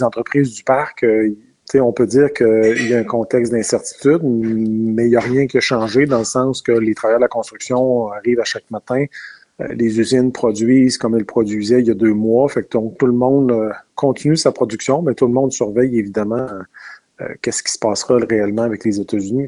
En entrevue, le président-directeur général a soutenu qu’il y avait aussi de très bonnes nouvelles, mais que celles-ci passaient souvent sous le silence.